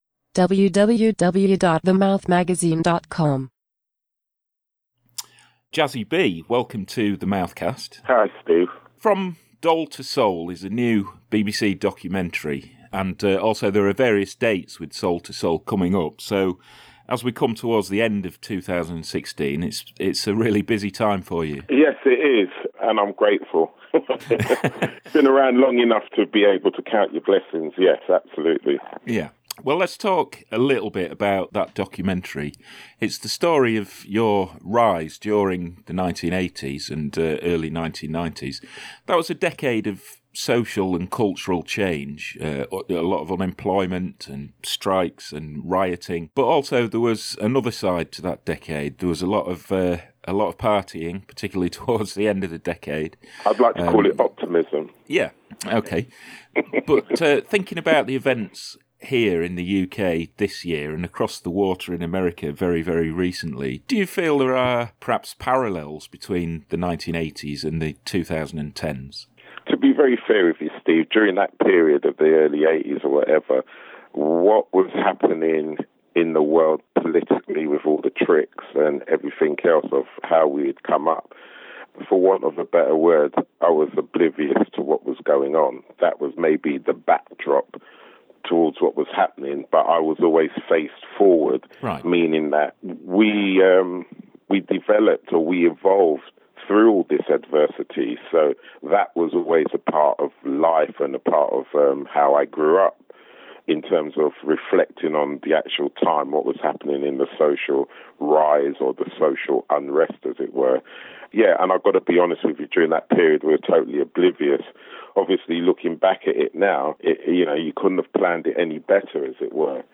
In this brief but beautiful new edition of The Mouthcast – recorded just ahead of the transmission of a special BBC documentary (FROM DOLE TO SOUL) – driving force Jazzie B reflects on the album, and how Soul II Soul transitioned from being a reggae sound-system to a full band…